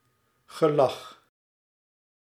Ääntäminen
Vaihtoehtoiset kirjoitusmuodot смѣ́хъ Ääntäminen Tuntematon aksentti: IPA: /smʲex/ Haettu sana löytyi näillä lähdekielillä: venäjä Käännös Ääninäyte 1. lach {m} 2. lachen {n} 3. gelach {n} Translitterointi: smeh.